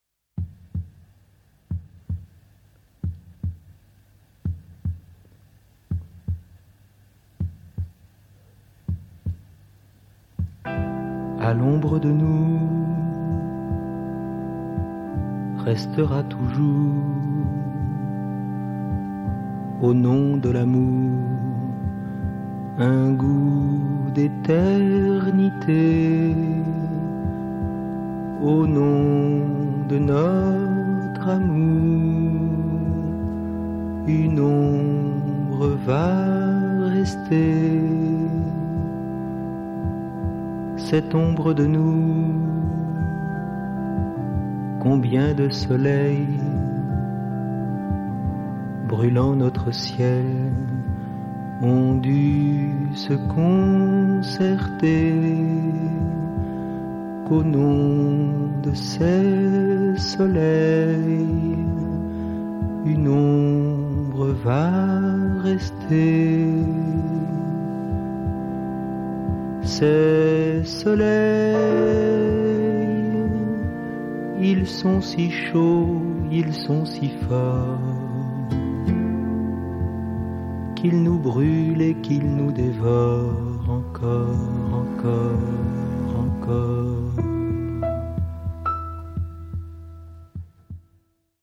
the melodies are stunningly beautiful.